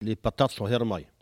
Patois
Catégorie Locution